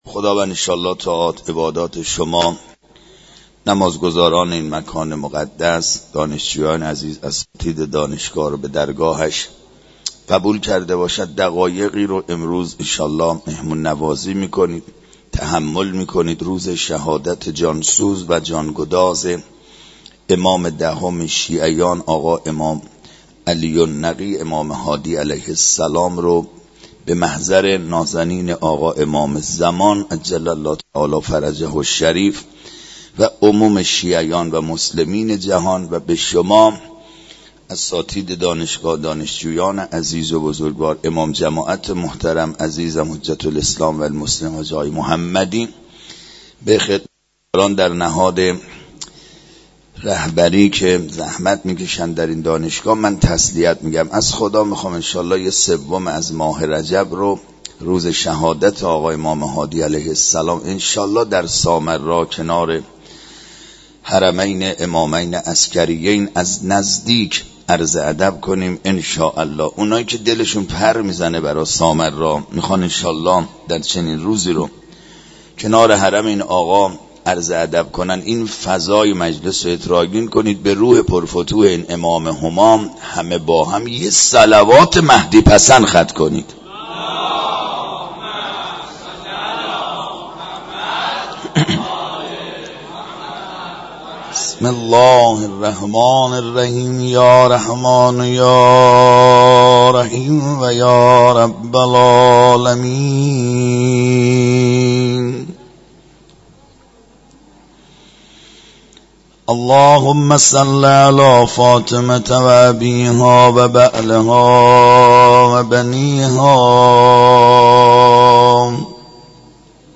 مراسم عزاداری به مناسبت شهادت امام هادی علیه السلام در مسجد دانشگاه کاشان برگزار گردید.
مراسم عزاداری به مناسبت شهادت امام هادی علیه السلام در مسجد دانشگاه کاشان با مداحی